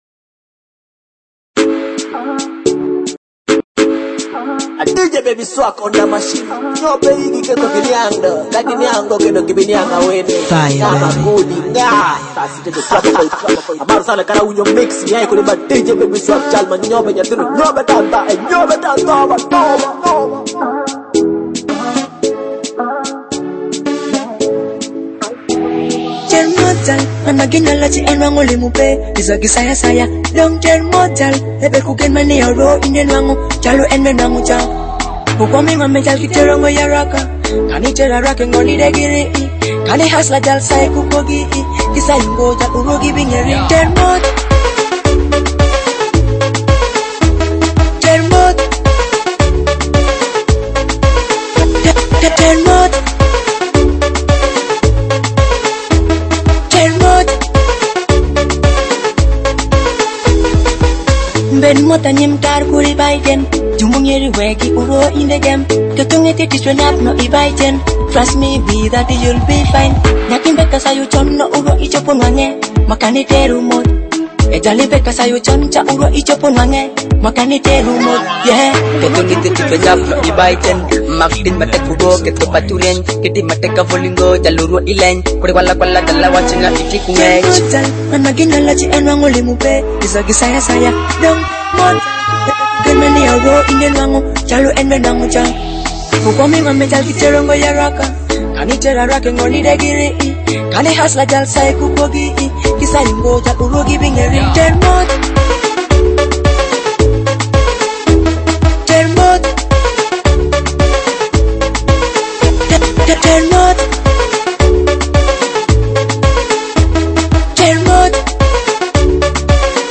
Latest Alur Music